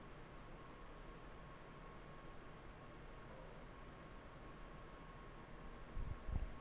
In that very same area of the production building, we also caught 2 interesting videos and an EVP.
Maybe. We caught a couple of interesting videos, an EVP of the name Paul, and some seemingly intelligent responses on our equipment.